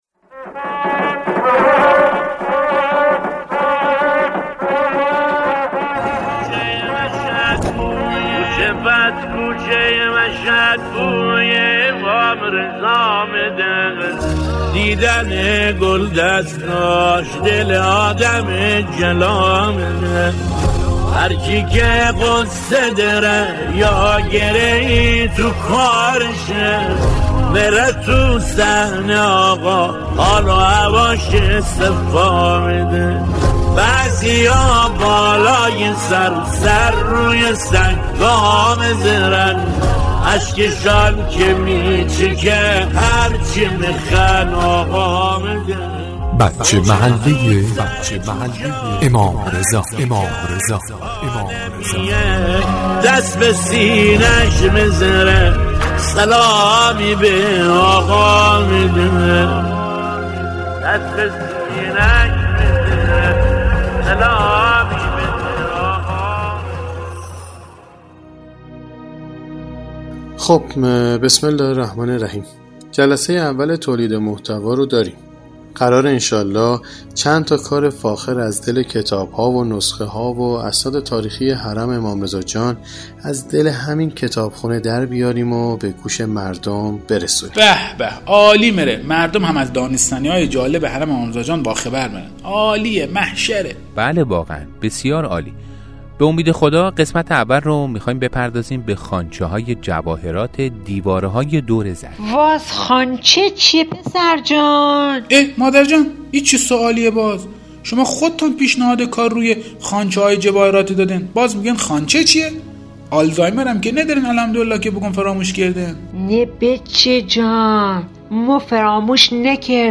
نمایشنامه بچه محله امام رضا (ع)؛
در فصل دوم نمایشنامه بچه محله امام رضا(ع) ، به موضوع خوانچه های جواهرات حرم مطهر رضوی پرداخته شده است که با اجرای خادمیاران تهیه و تولید گردید.